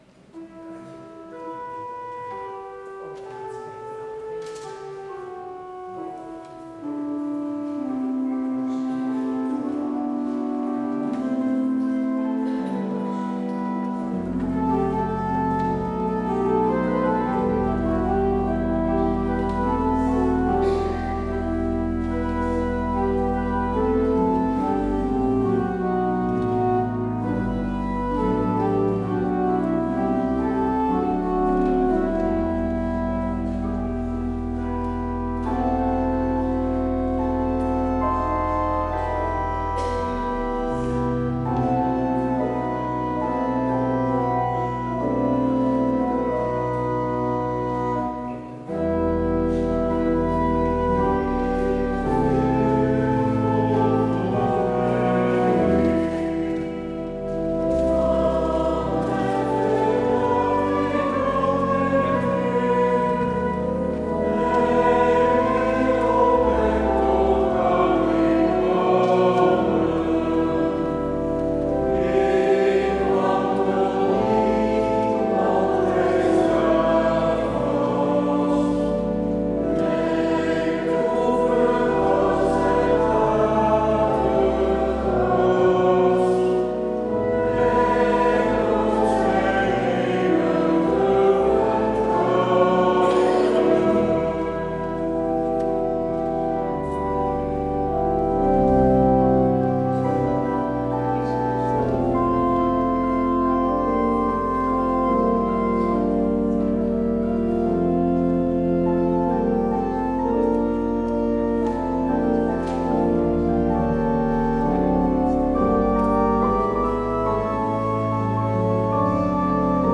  Beluister deze kerkdienst hier: Alle-Dag-Kerk 1 oktober 2025 Alle-Dag-Kerk https